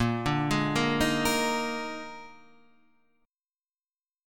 A# 11th